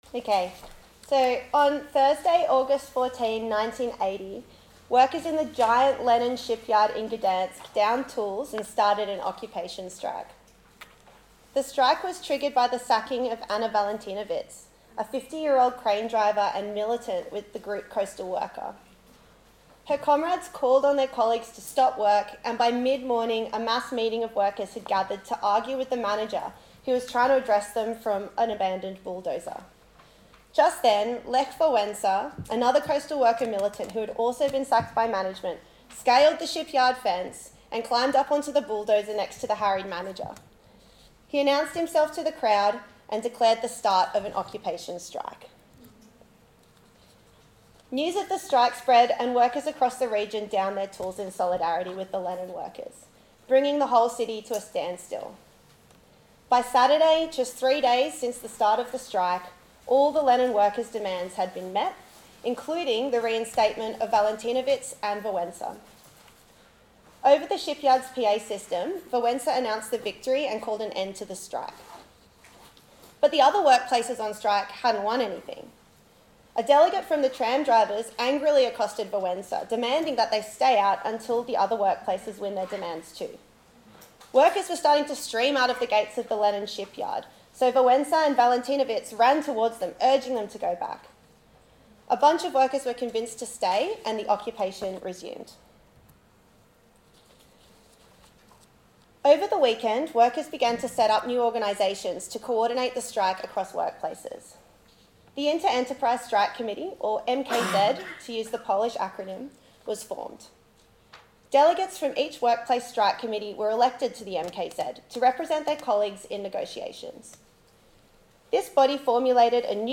Socialism 2025 (Sydney)